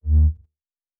pgs/Assets/Audio/Sci-Fi Sounds/Weapons/Lightsaber 1_1.wav at master
Lightsaber 1_1.wav